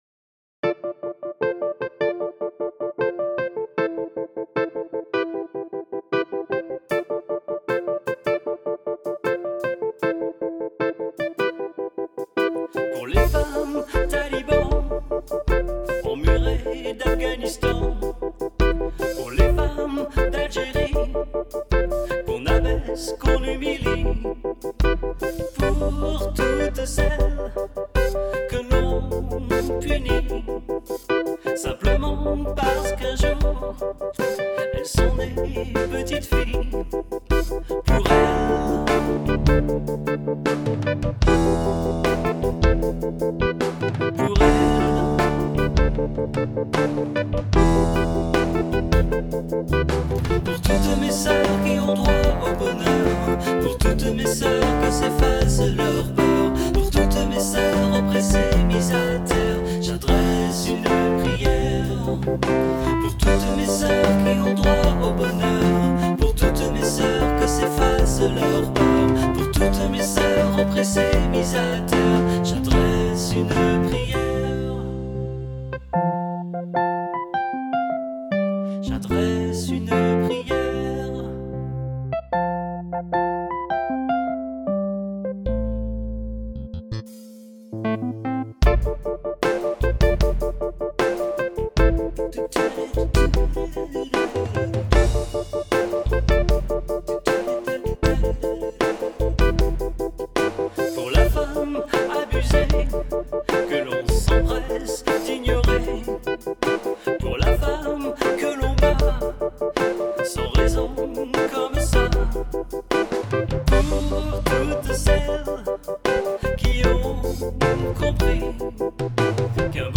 Here are the full (for fast internet connexions...) demo mp3 versions of these two songs... :